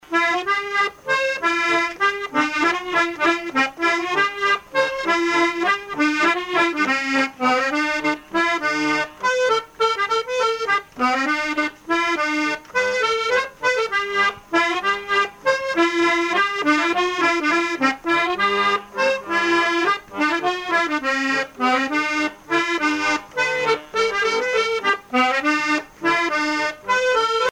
Chants brefs - A danser
danse : mazurka
musique à danser à l'accordéon diatonique
Pièce musicale inédite